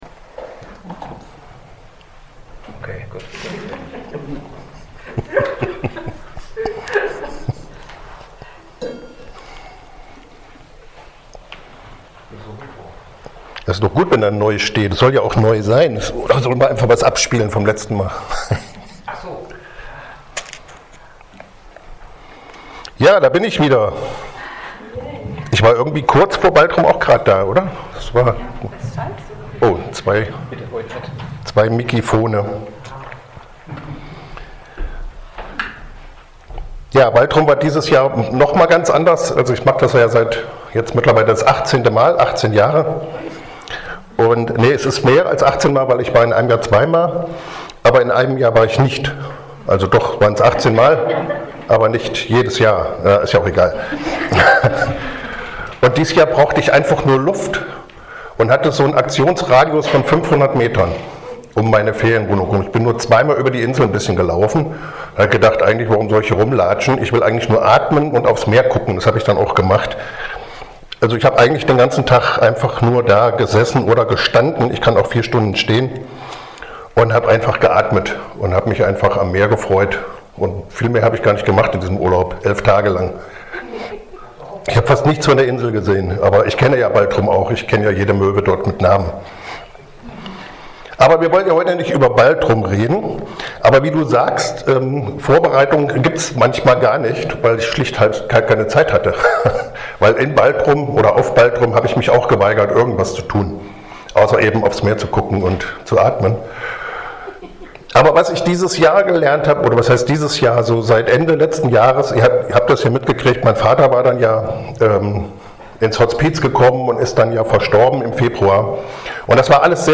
Dienstart: Externe Prediger